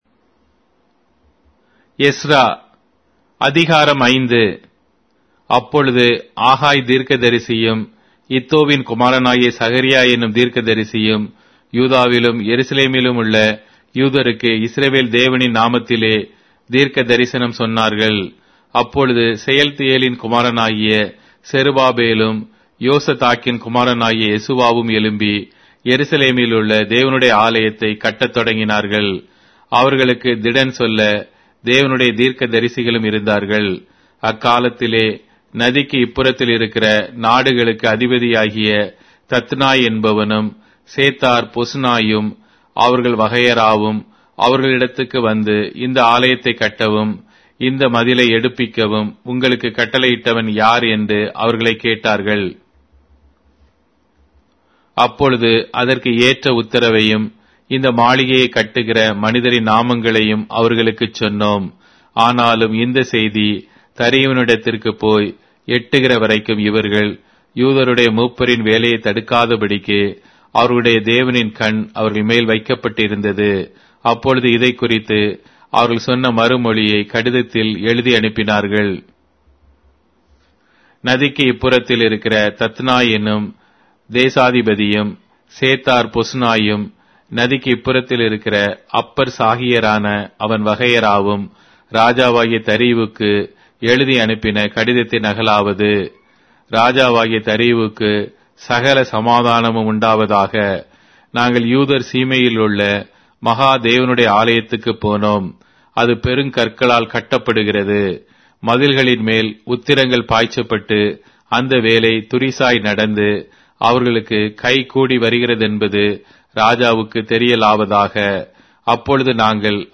Tamil Audio Bible - Ezra 8 in Ylt bible version